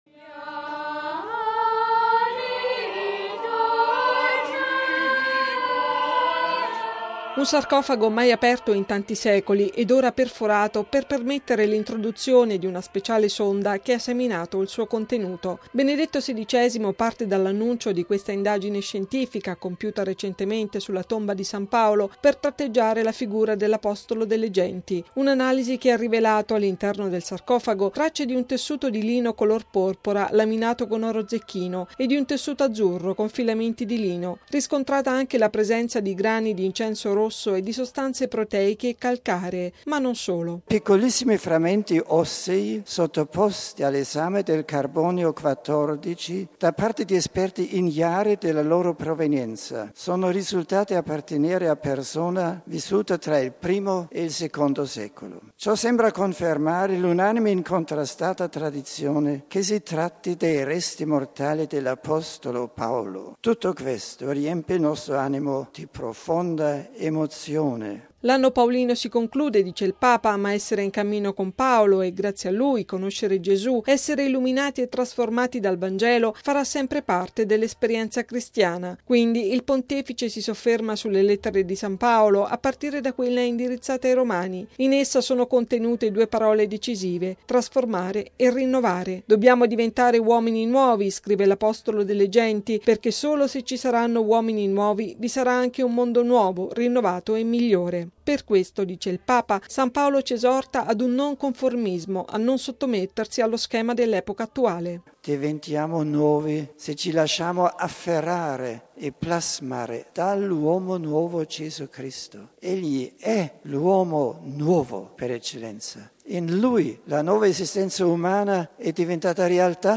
(canto)